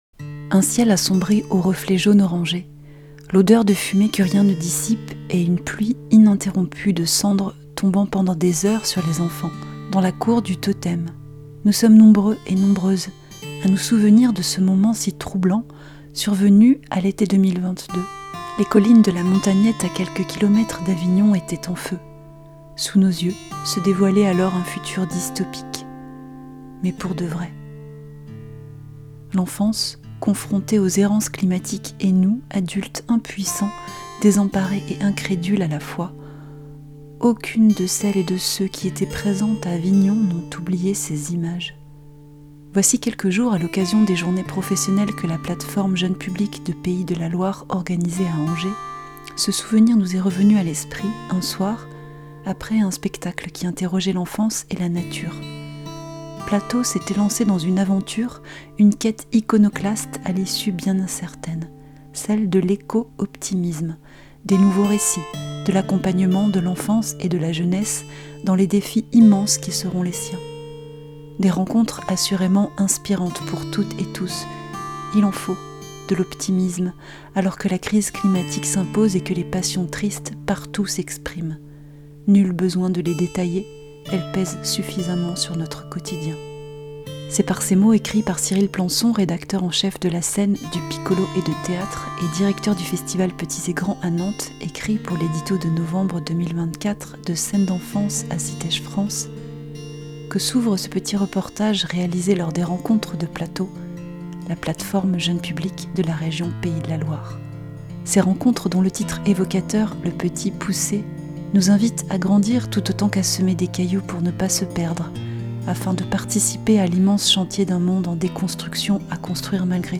le-petit-poussait-rencontres-plato-2024.mp3